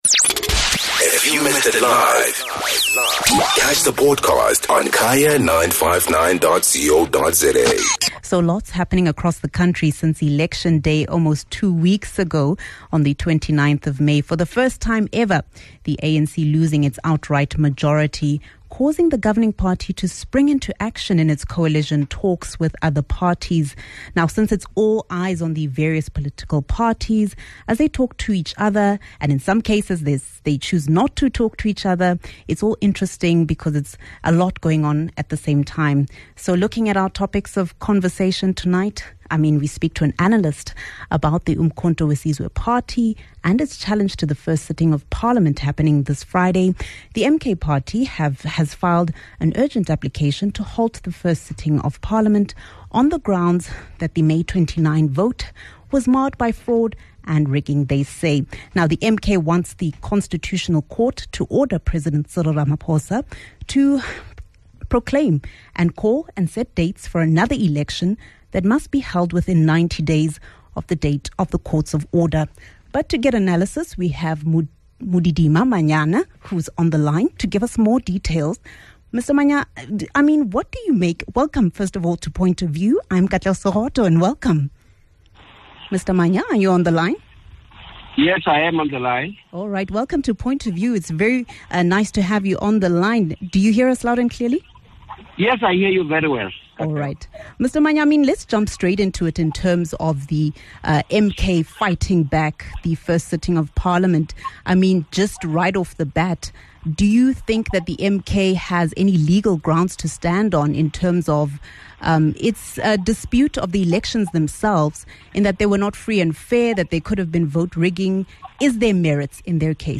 Legal Analyst